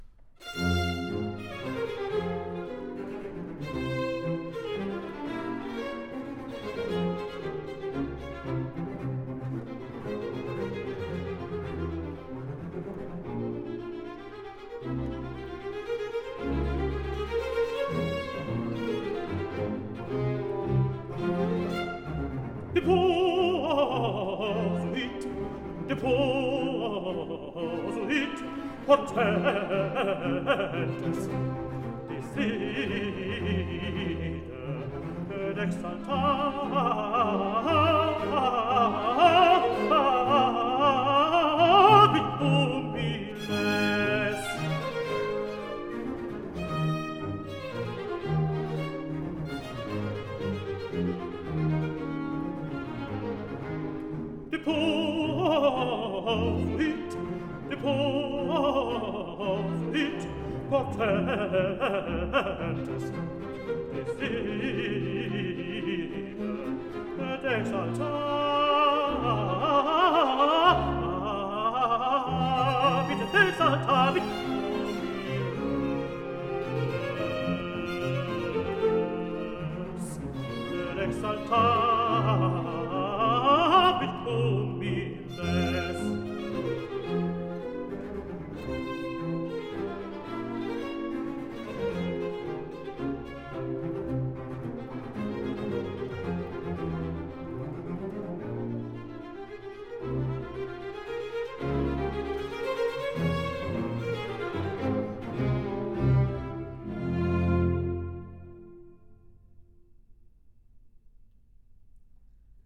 Deposuit potentes: Tenor